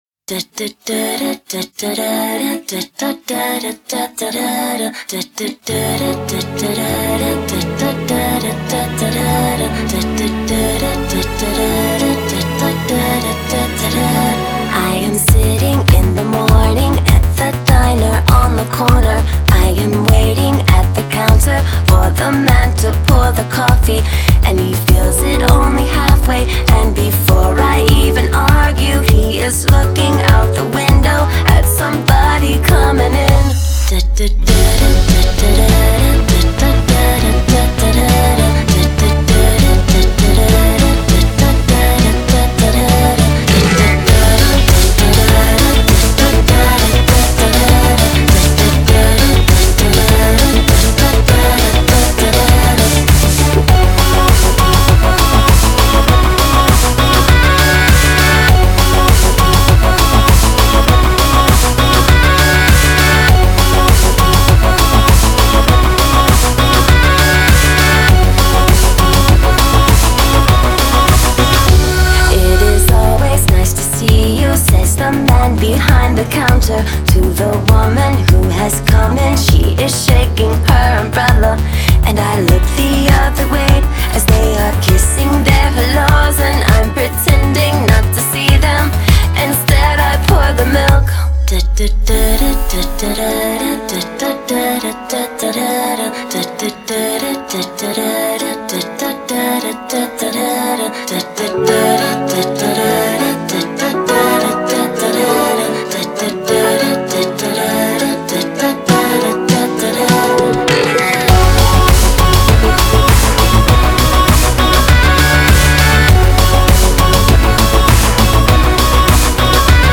Жанр: Electronic